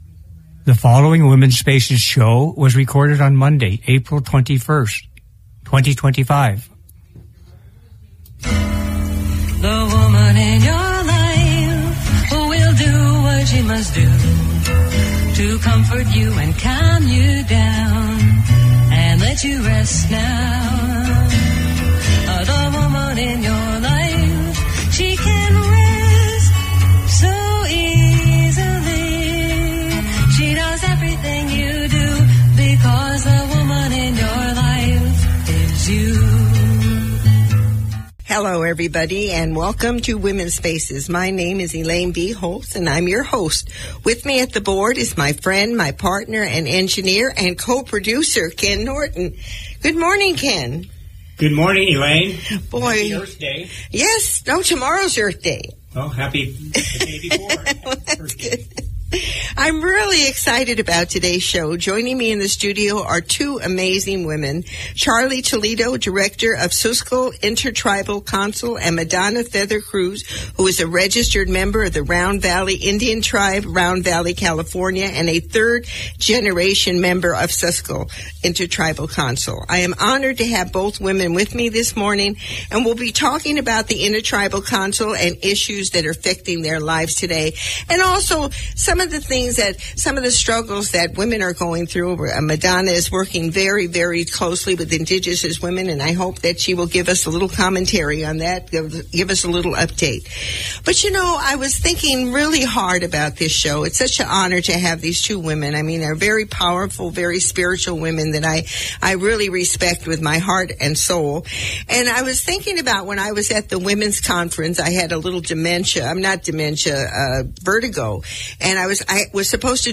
Opening Prayer Song